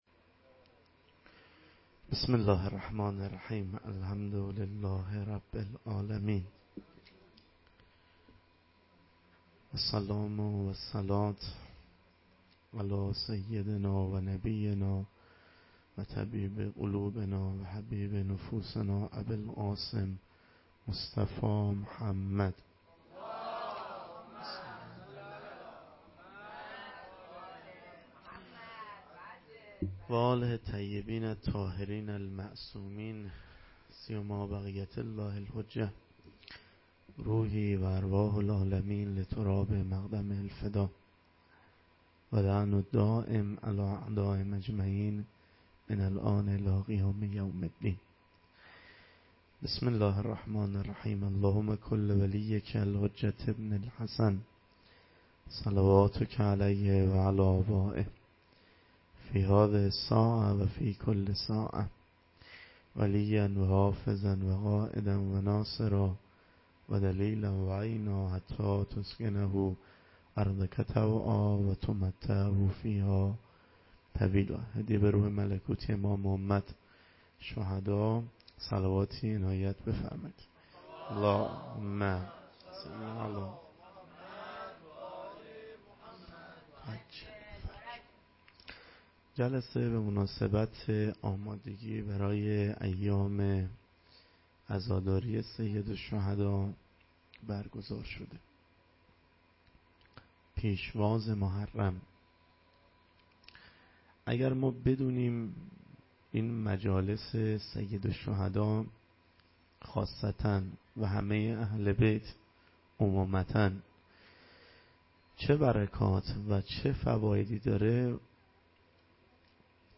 سخنرانی مراسم پیشواز محرم 1398